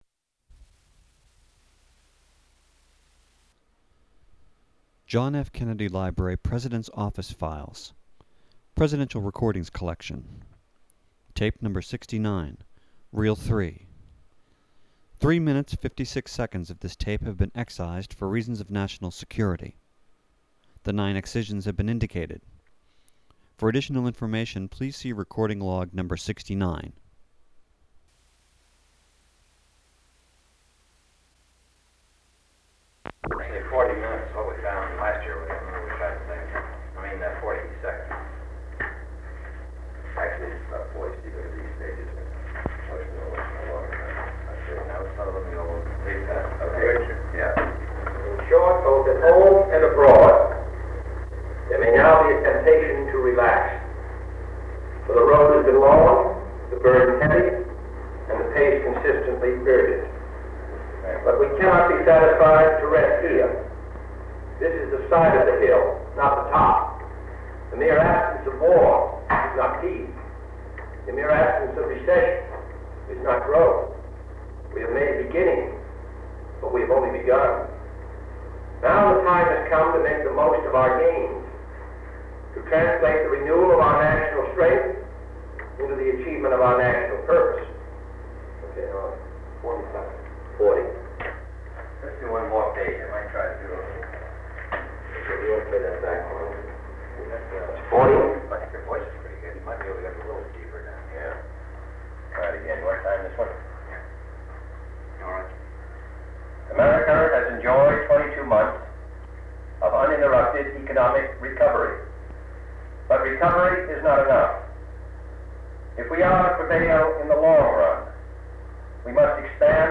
Secret White House Tapes | John F. Kennedy Presidency Meeting with the Joint Chiefs of Staff Rewind 10 seconds Play/Pause Fast-forward 10 seconds 0:00 Download audio Previous Meetings: Tape 121/A57.